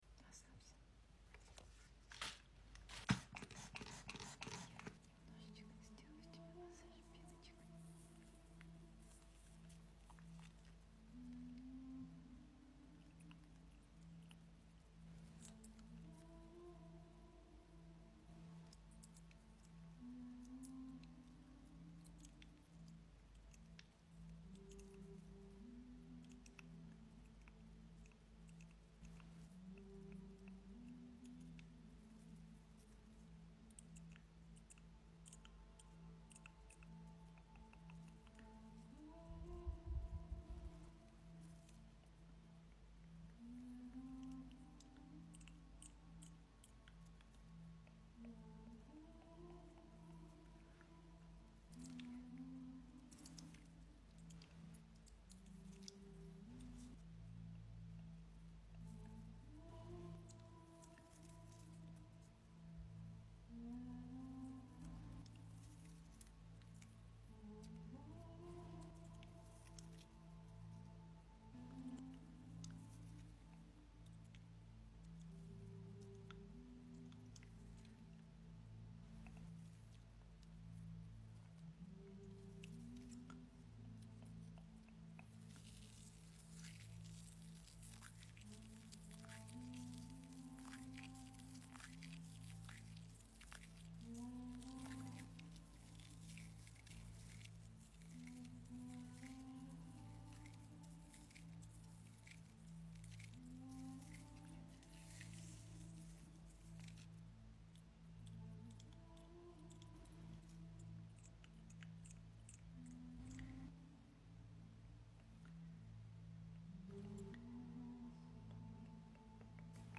ASMR, but now I'm going sound effects free download
ASMR, but now I'm going to give you a foam massage for relaxation, confirm that you liked it.